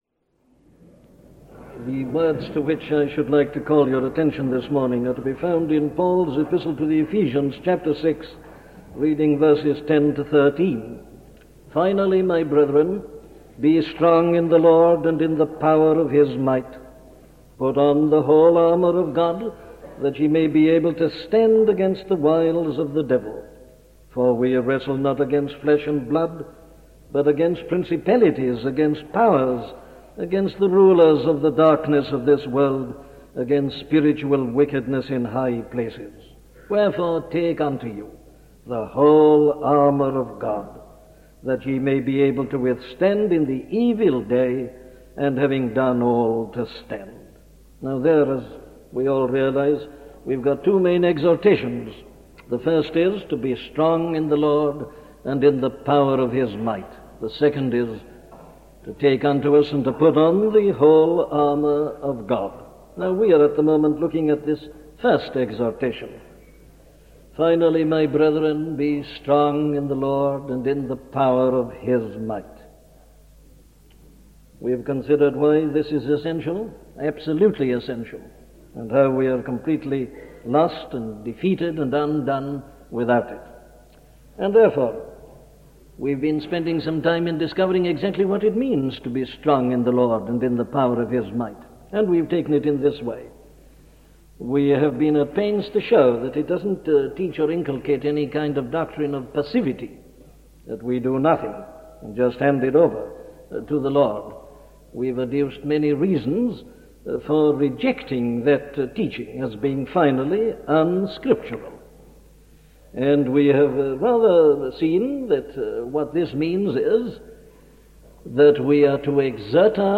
Trust in God and ... - a sermon from Dr. Martyn Lloyd Jones